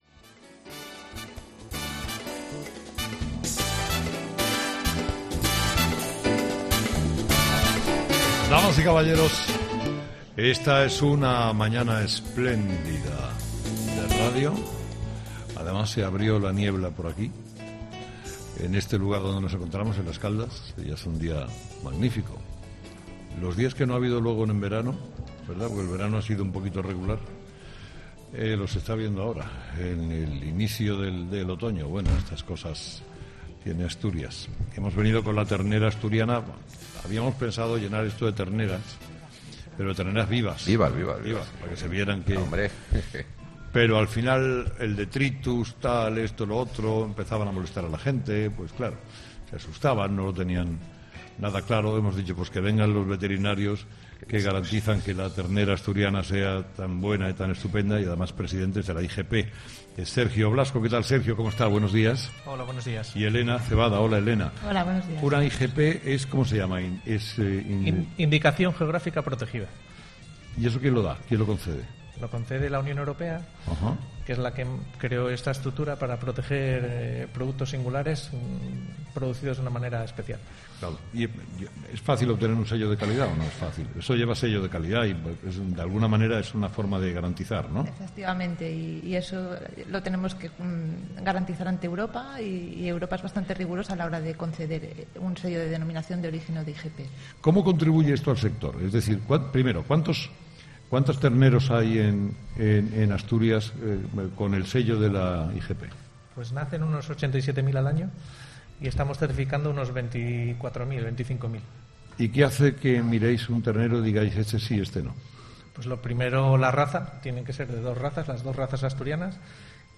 'Herrera en COPE' se emite este lunes desde las Caldas Villa Termal (Oviedo), tierra del cachopo de ternera.